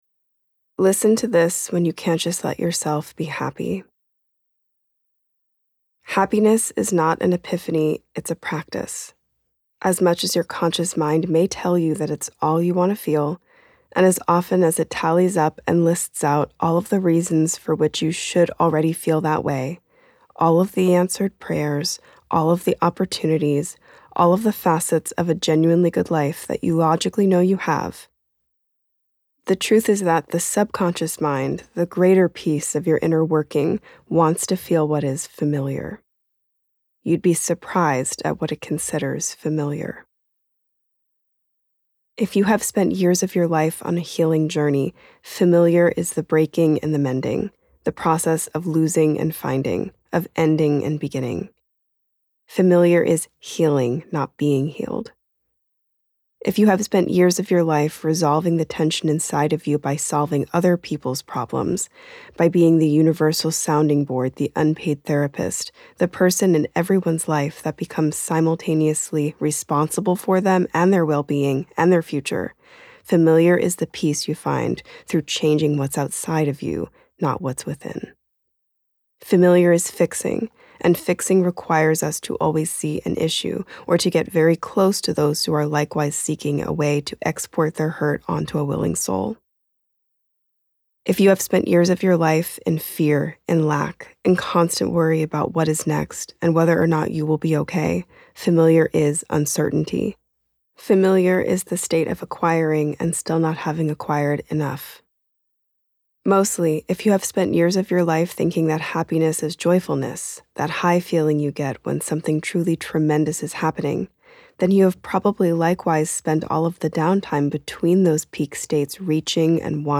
• Narrated by Brianna Wiest
• Audiobook • 2 hrs, 54 mins